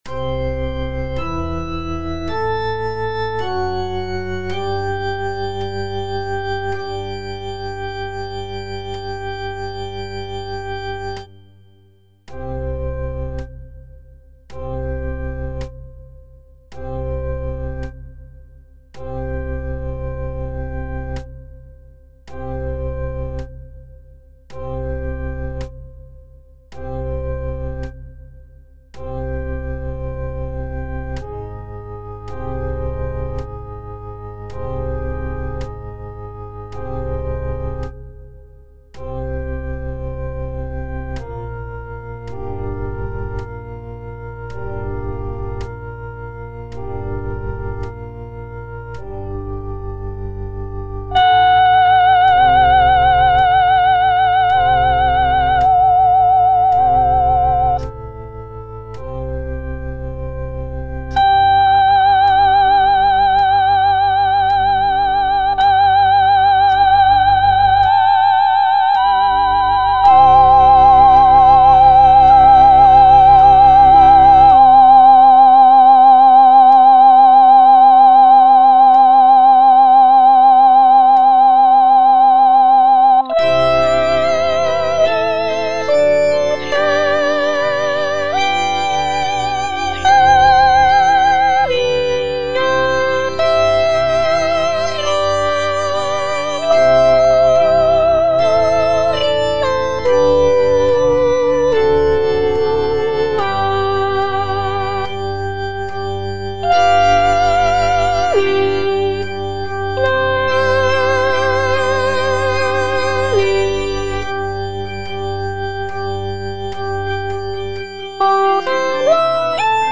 C.M. VON WEBER - MISSA SANCTA NO.1 Sanctus (soprano I) (Voice with metronome) Ads stop: auto-stop
"Missa sancta no. 1" by Carl Maria von Weber is a sacred choral work composed in 1818.